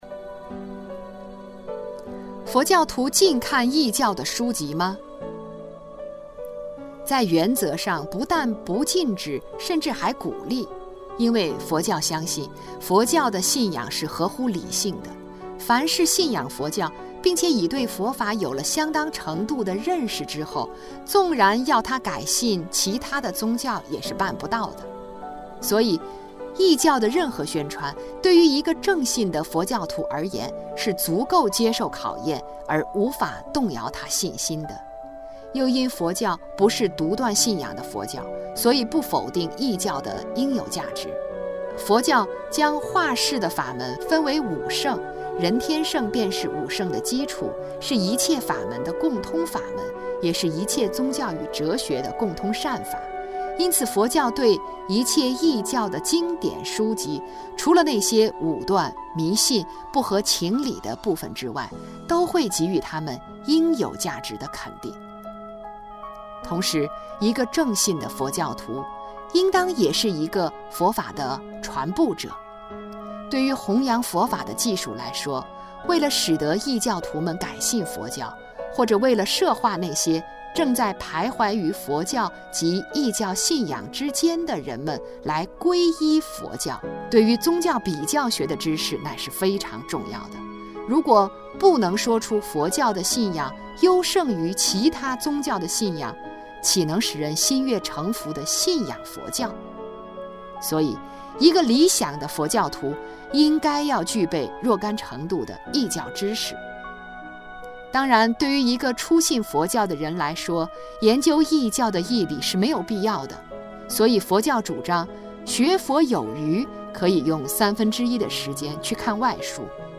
网上柏林 > 问禅寮 > 有声书库 > 圣严法师《正信的佛教》 > 61佛教徒禁看异教的书籍吗？